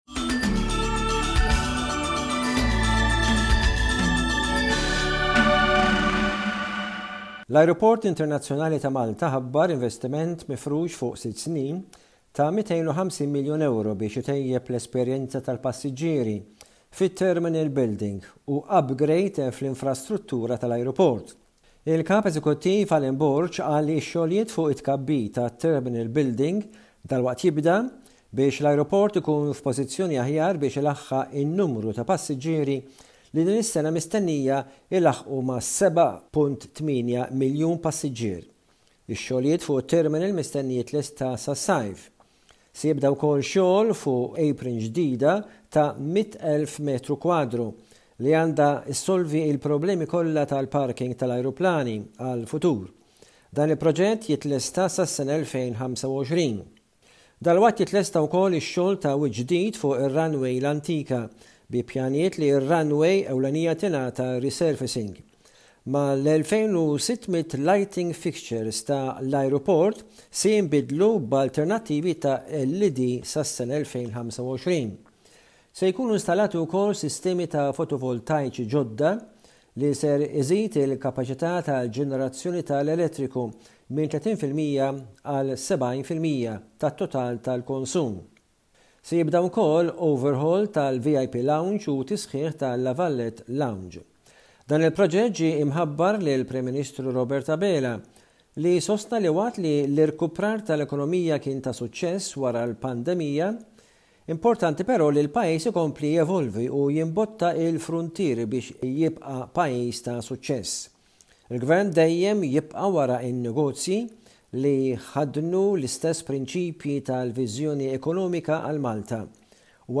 News report